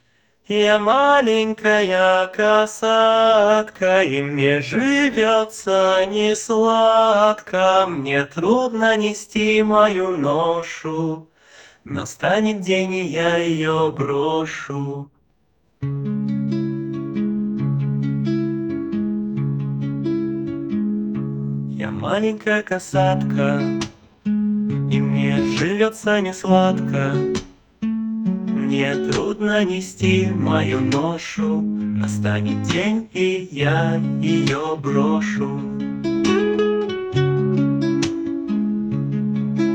Кстати вы вкурсе, что через суно можно петь песни чужими голосами?